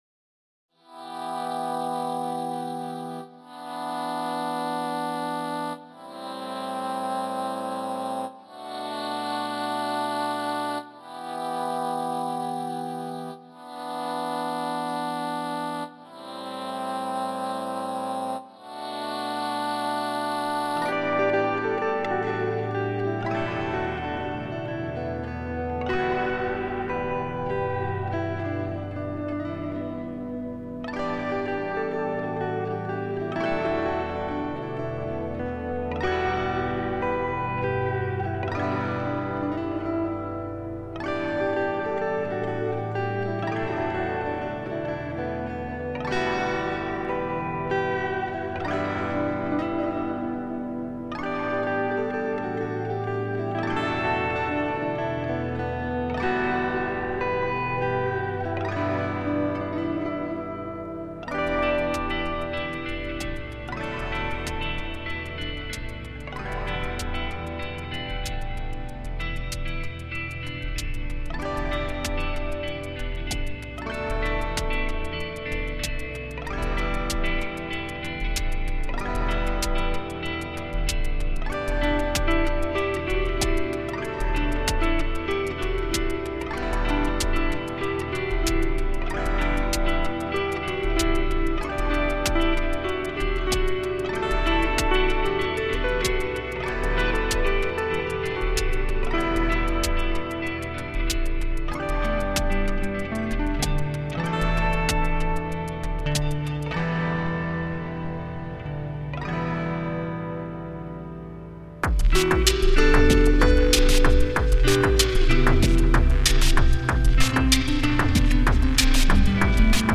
4. Genre: Electronic